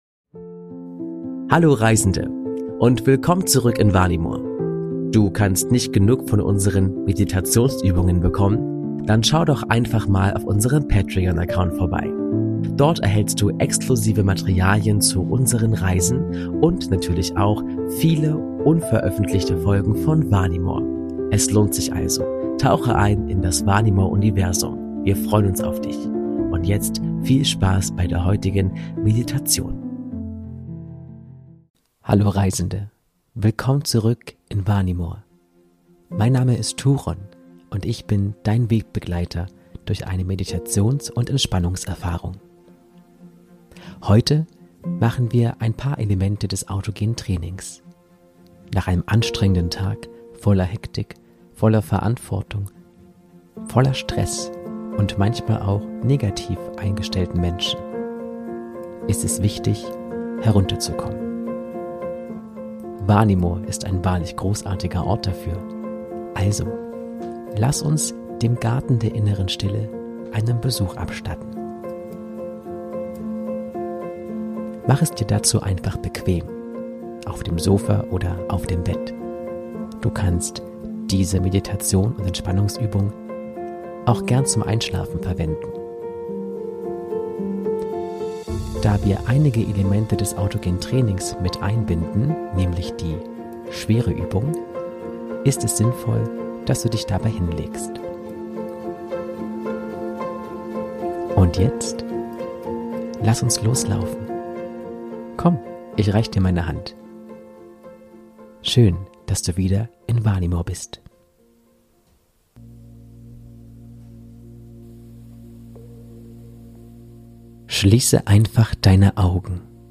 Meditation: Im magische Garten von Vanimor // Autogenes Training ~ Vanimor - Seele des Friedens Podcast
Diese Episode enthält Elemente des autogenen Trainings.